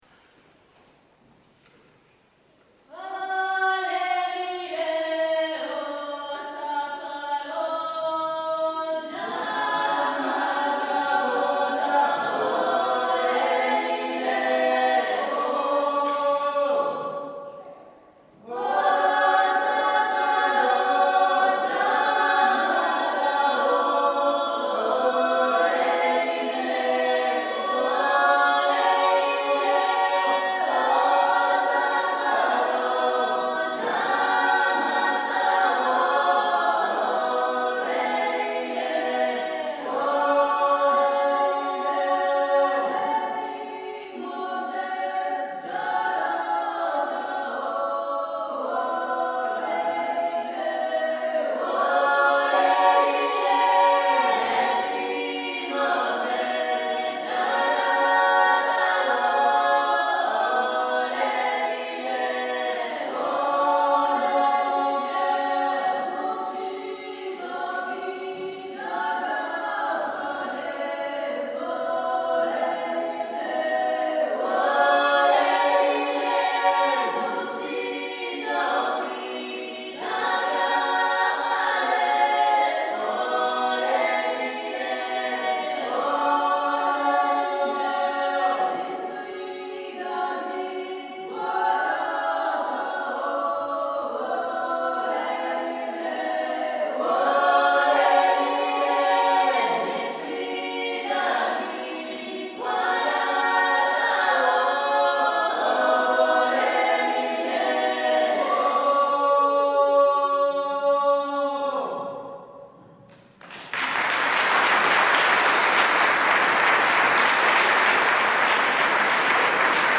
concerts de polyphonies du monde
4 à 10 chanteurs
extraits audio de concerts
enregistrés à Chaudes Aigues, août 06 & à Trets, novembre 06